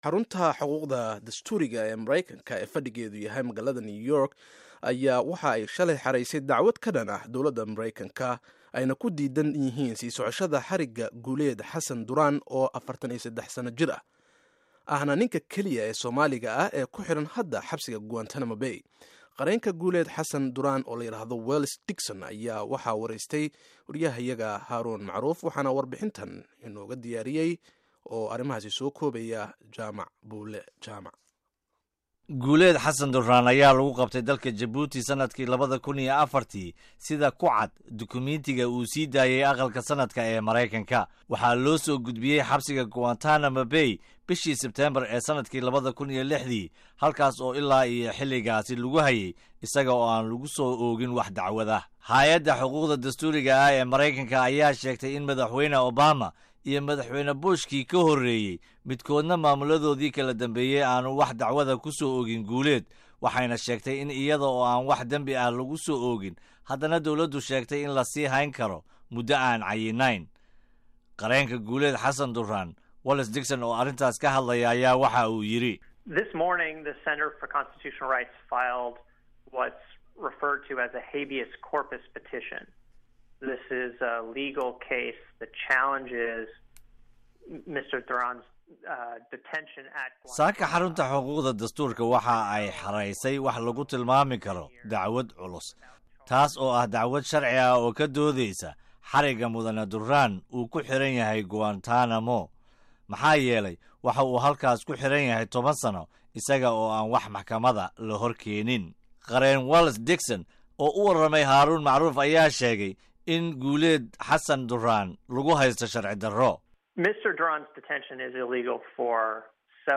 Warbixinta